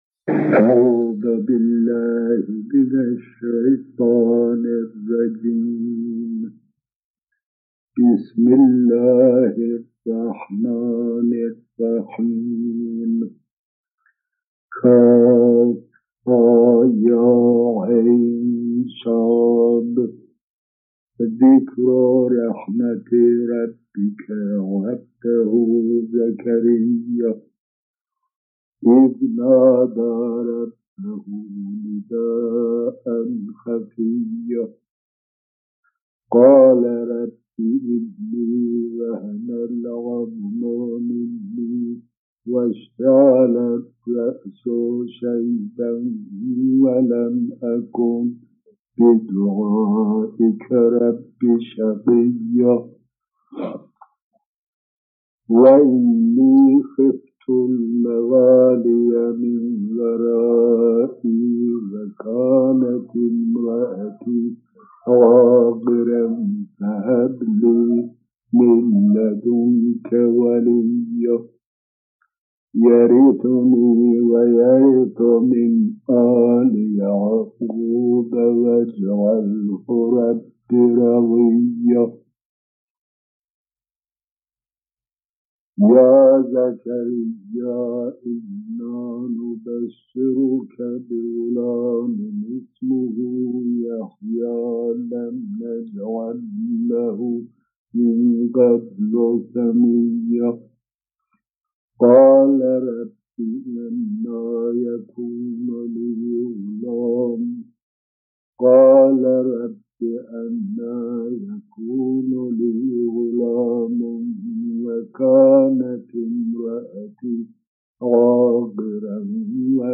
فیلم | تلاوت سوره مریم با صوت علامه طباطبایی و تصدیق «صدق الله العظیم»
تلاوت سوره مریم از آیات 1 تا 50 با صدای علامه محمدحسین طباطبایی، مفسر قرآن کریم را می‌شنویم. علامه طباطبایی در این قرائت ساده با عبارت «صدق الله عظیم» تلاوت خود را به پایان می‌رساند.